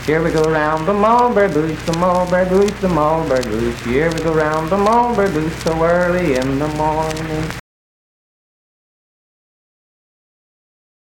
Unaccompanied vocal performance
Verse-refrain 1(4).
Dance, Game, and Party Songs
Voice (sung)
Roane County (W. Va.), Spencer (W. Va.)